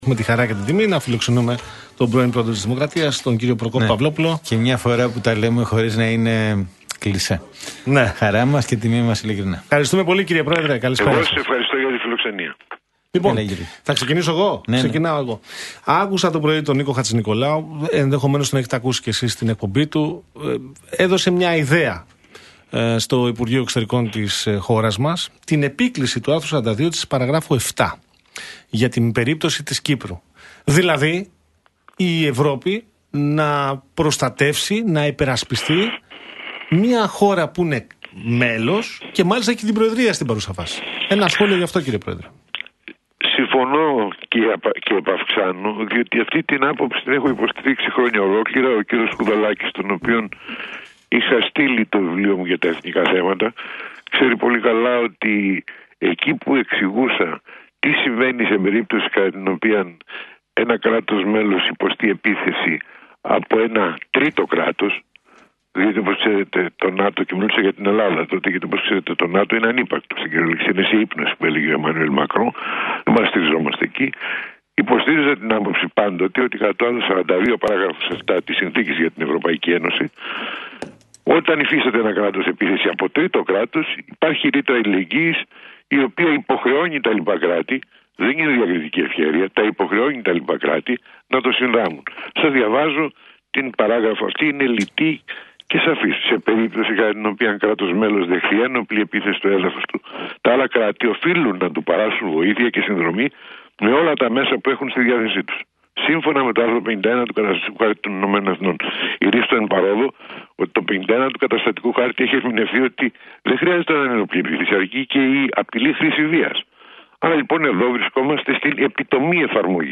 υπογράμμισε απόψε στον αέρα του Real FM 97.8